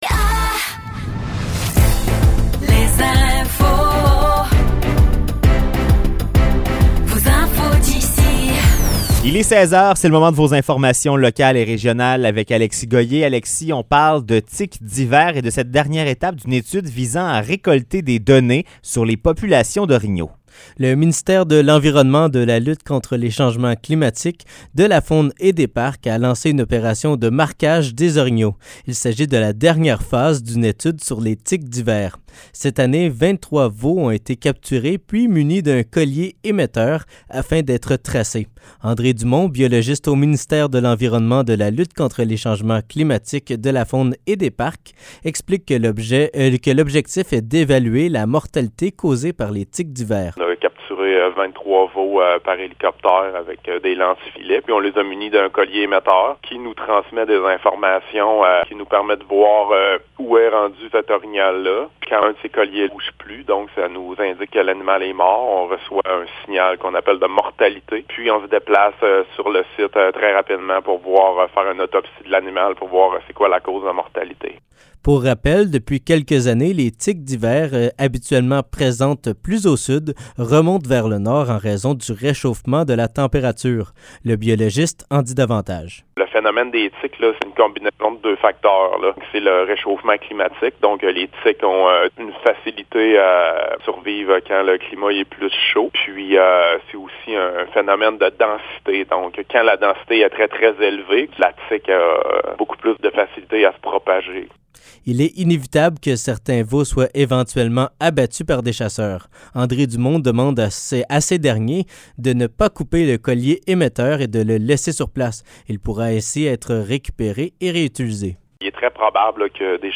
Nouvelles locales - 20 septembre 2023 - 16 h